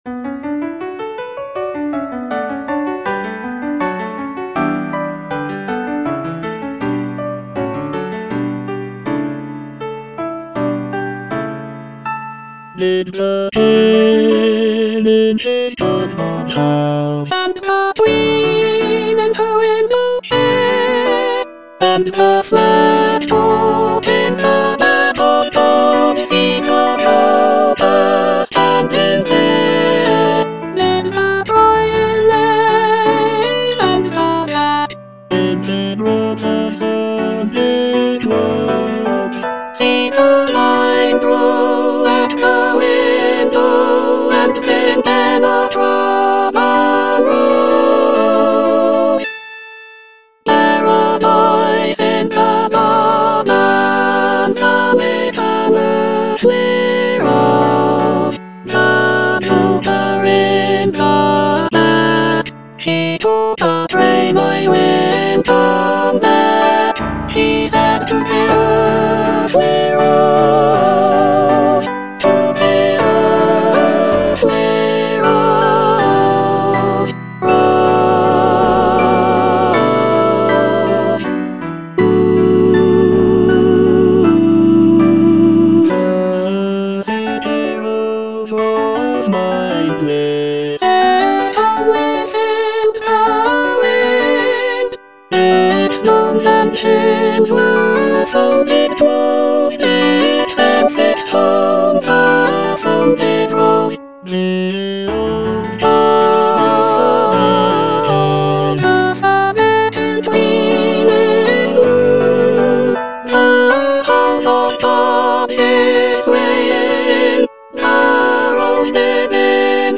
Soprano II (Emphasised voice and other voices)
choral work